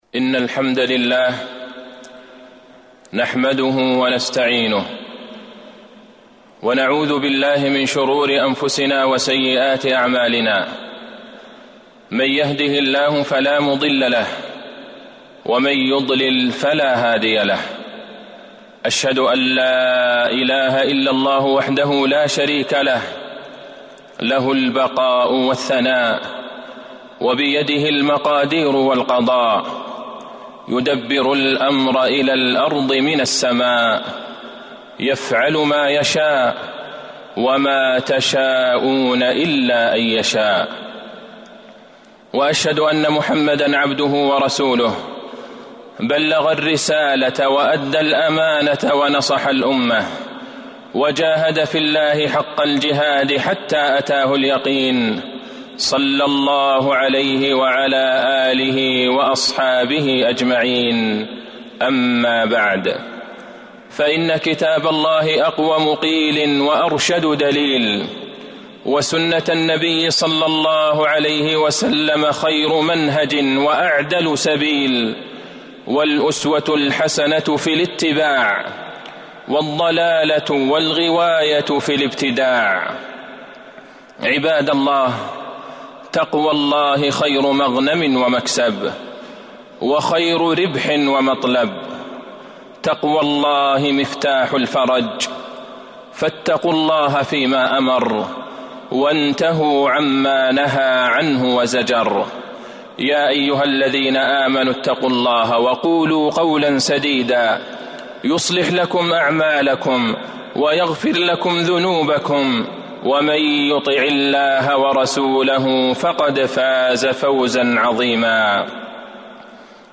تاريخ النشر ٨ صفر ١٤٤٢ هـ المكان: المسجد النبوي الشيخ: فضيلة الشيخ د. عبدالله بن عبدالرحمن البعيجان فضيلة الشيخ د. عبدالله بن عبدالرحمن البعيجان فضل صلاة الجماعة